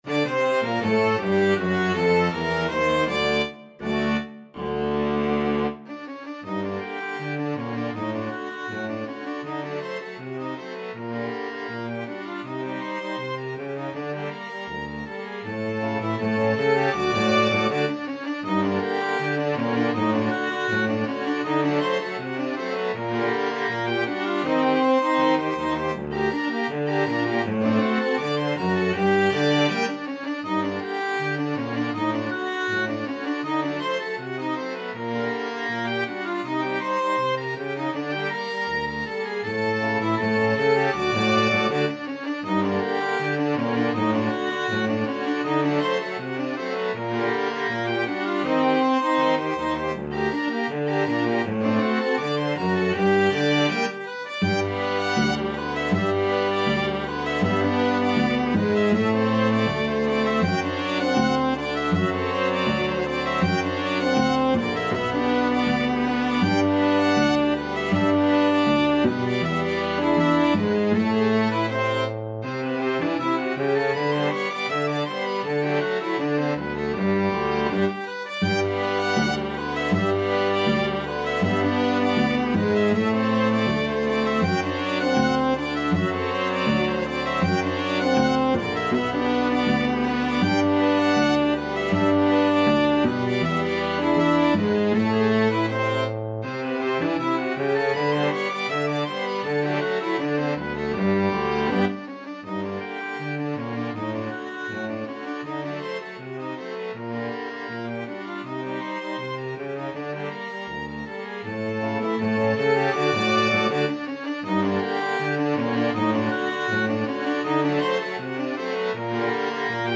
OboeViolin
TrumpetViolin
French HornViola
TubaCello
2/4 (View more 2/4 Music)
Brazilian